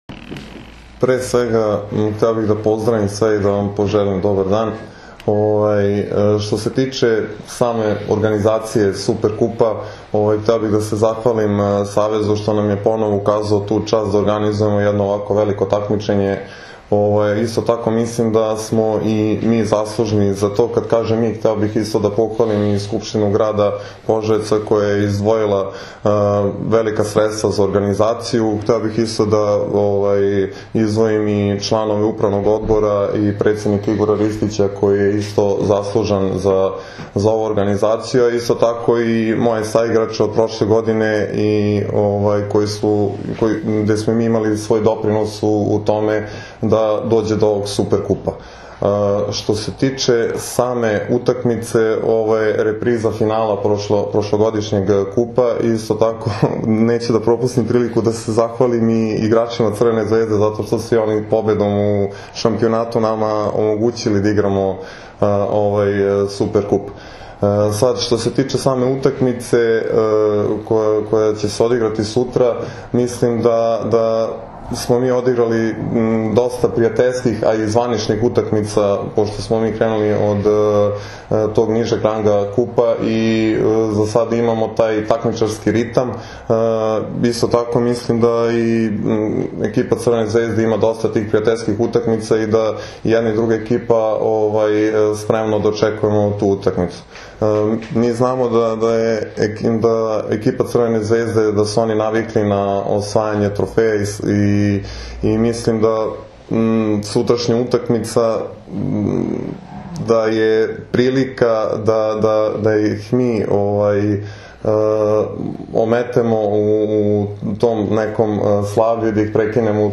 U prostorijama Odbojkaškog saveza Srbije danas je održana konferencija za novinare povodom utakmice VI Super Kupa Srbije 2016, koja će se odigrati sutra (četvrtak, 6. oktobar) od 18,00 časova u dvorani SC “Požarevac” u Požarevcu, između Crvene zvezde i Mladog radnika iz Požarevca, uz direktan prenos na RTS 2.
IZJAVA